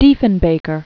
(dēfən-bākər), John George 1895-1979.